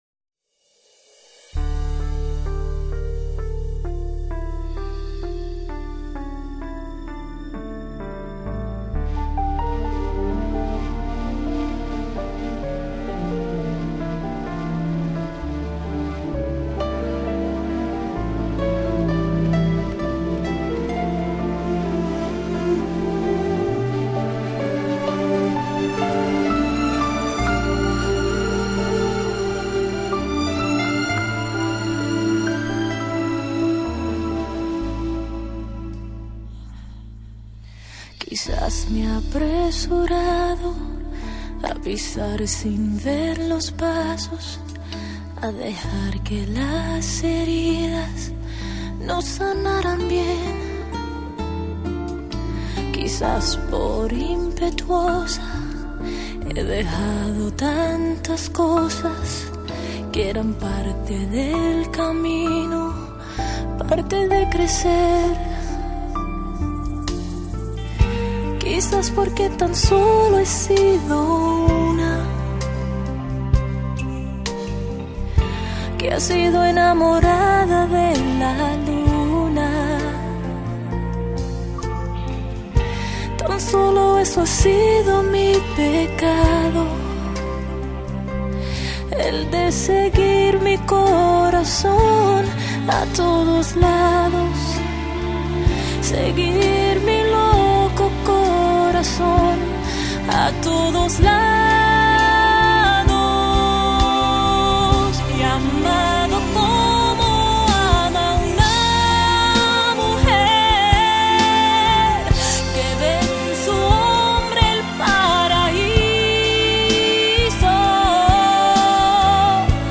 下面是我精选的十首经典西班牙语歌曲，曲风多为慢板抒情，希望大家喜欢。